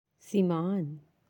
(simaan)